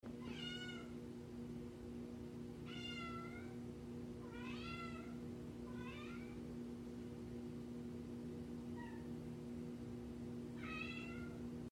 She Has The Cutest Meows🥹😺 Sound Effects Free Download